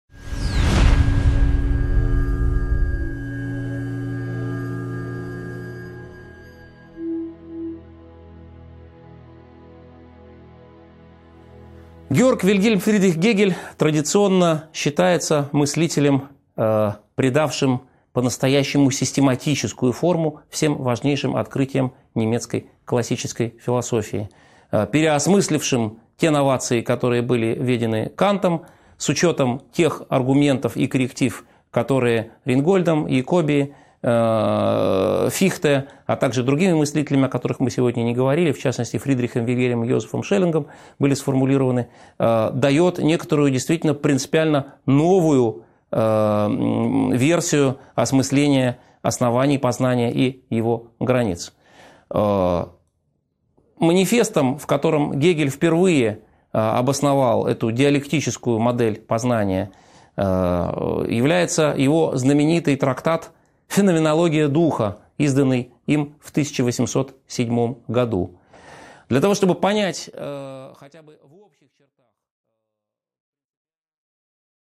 Aудиокнига 10.11 Диалектическая концепция познания.